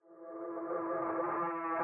PH - Rodeo (Fx).wav